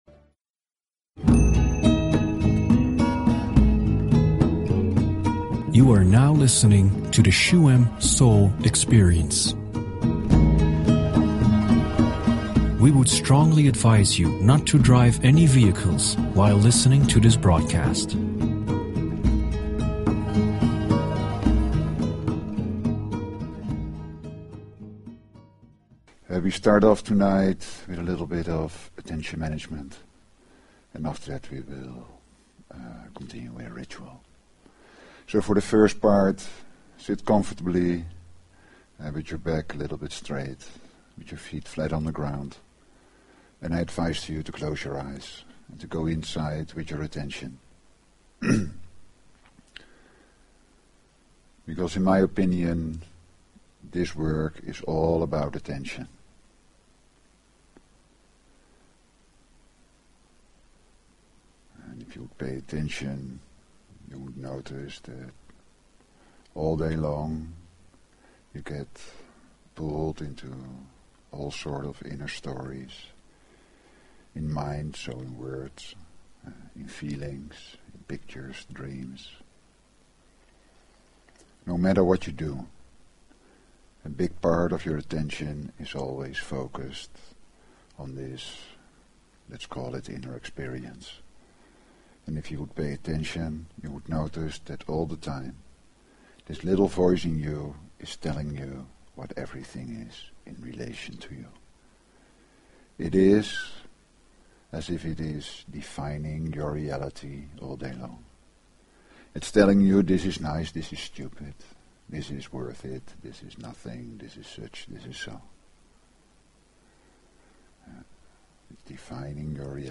Talk Show Episode, Audio Podcast, Shuem_Soul_Experience and Courtesy of BBS Radio on , show guests , about , categorized as
Shuem Soul Experience is a radio show with: